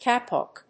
音節ka・pok 発音記号・読み方
/kéɪpɑk(米国英語), kéɪpɔk(英国英語)/